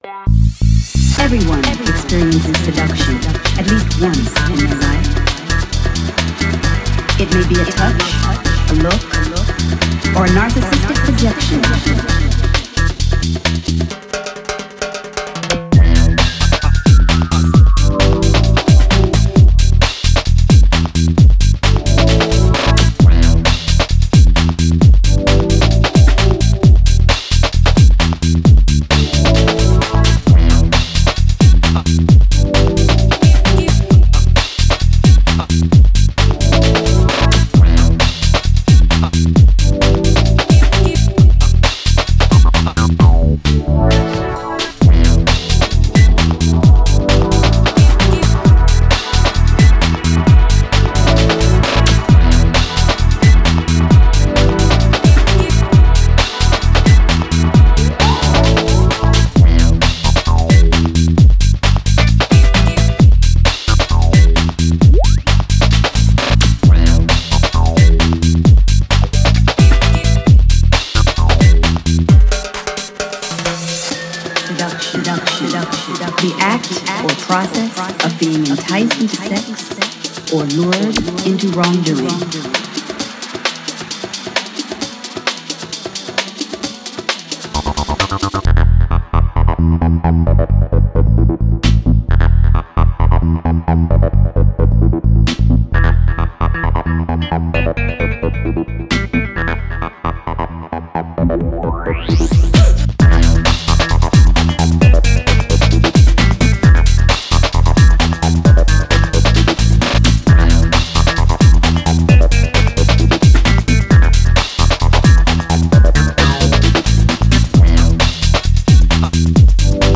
2x12inch Vinyl